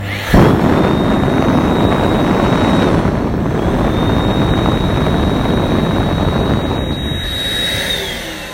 Our bathroom hand dryers are also very noisy.
Hand-Dryer.m4a